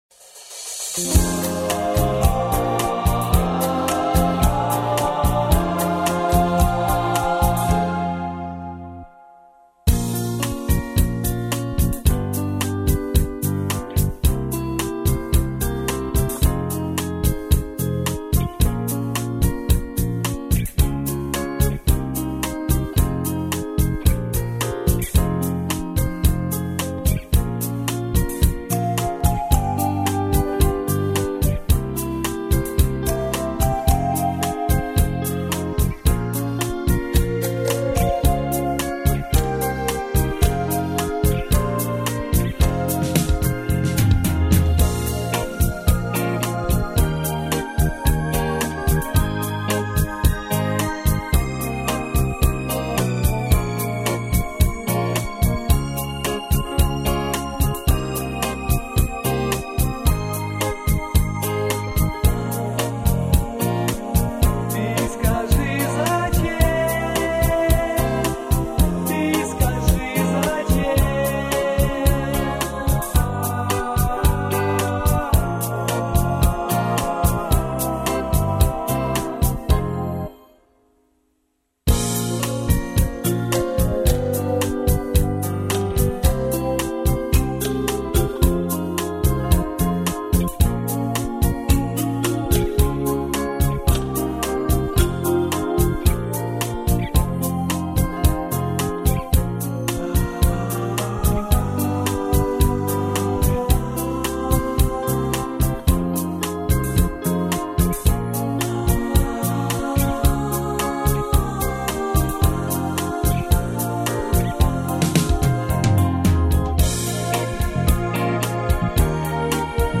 минусовка версия 231840